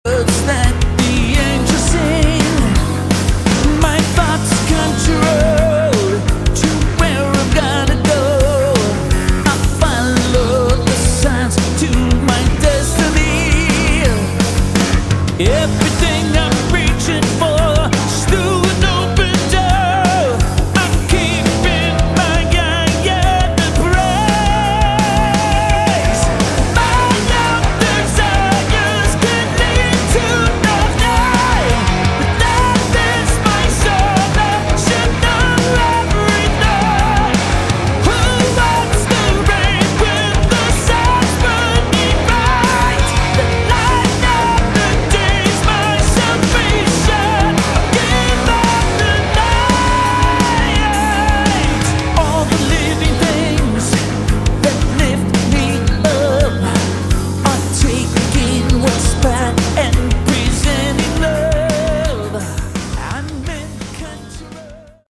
Category: Hard Rock
lead vocals, guitars
lead guitars
bass
drums